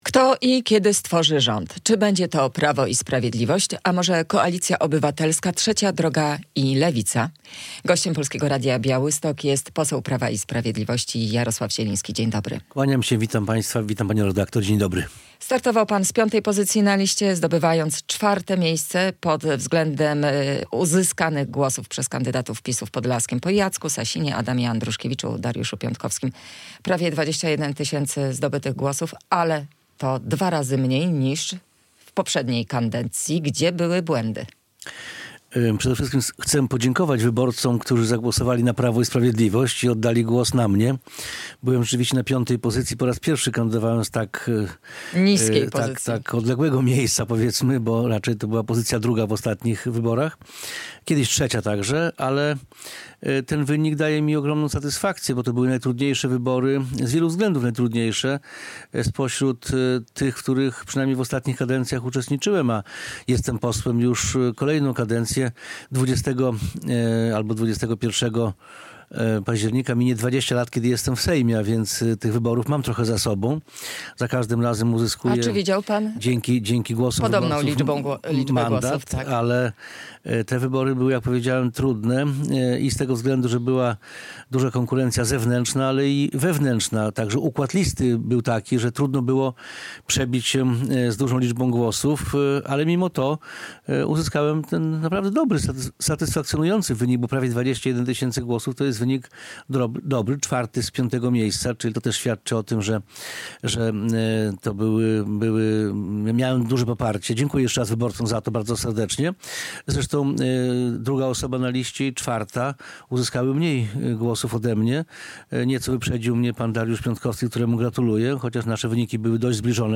Radio Białystok | Gość | Jarosław Zieliński [wideo] - poseł Prawa i Sprawiedliwości
poseł Prawa i Sprawiedliwości